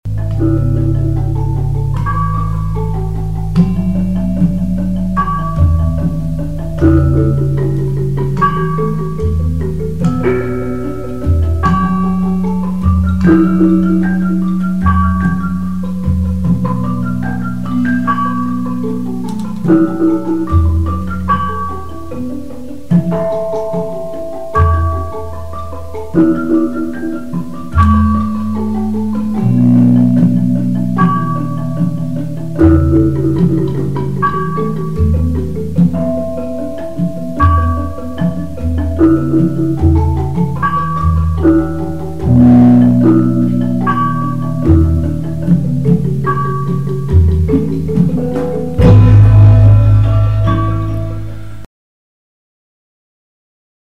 Javansk karawitan
Gambang.